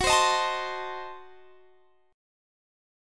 Listen to Gbdim7 strummed